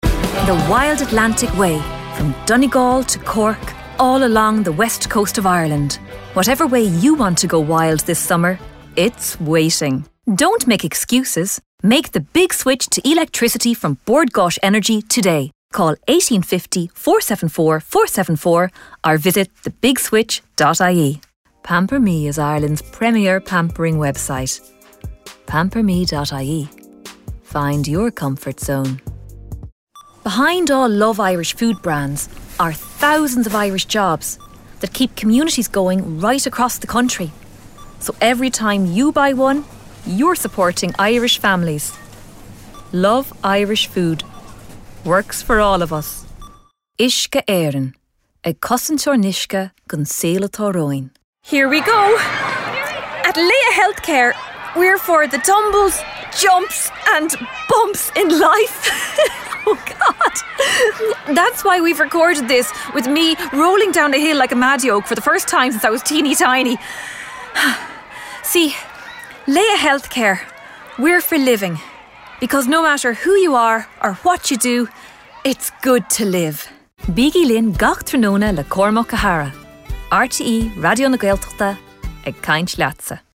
Female
AUTHORITATIVE, SINCERE, CLEAR, COMPASSIONATE, INTELLIGENT READ, ANIMATION CHARACTERS.
30s/40s, 40s/50s
Irish Galway, Irish Neutral